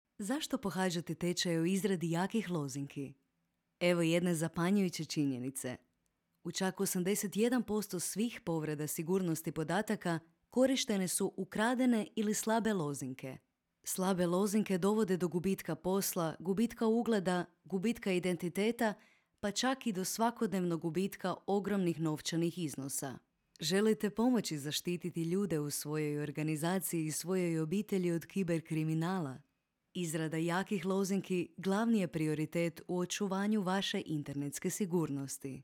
Versátil, Amable, Cálida, Travieso, Empresarial
E-learning